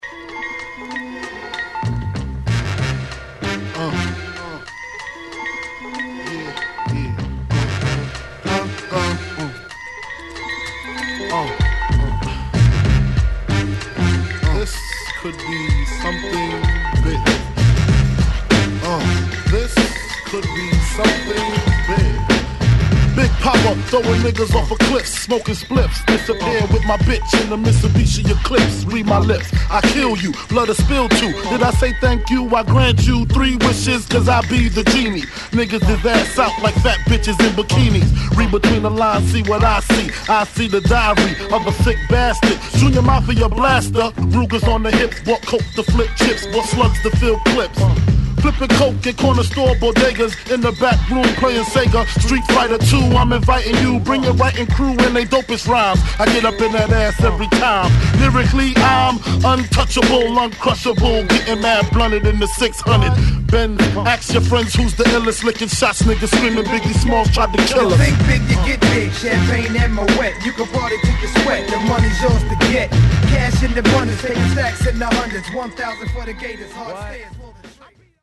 ホーム HIP HOP 90's 12' & LP P